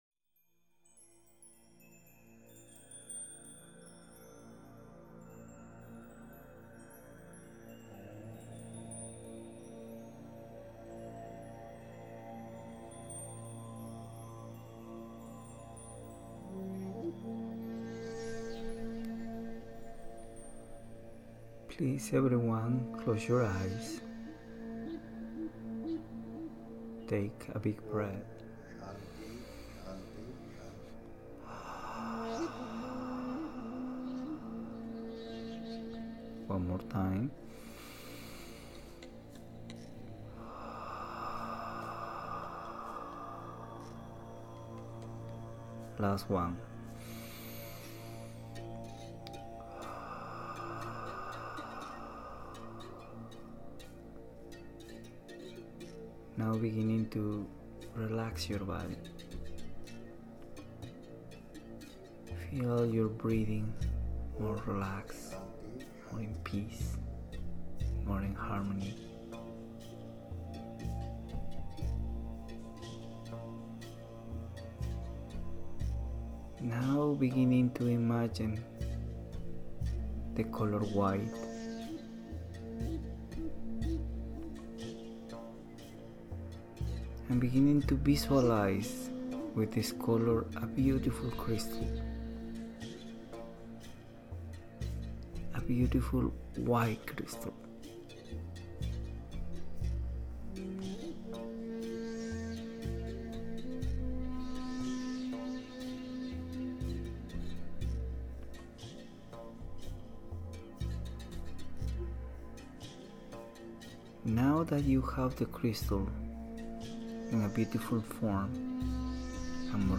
Guided Meditation Connecting to the Sacred Fire of Love This meditation can be streamed directly below, or also downloaded to your personal device.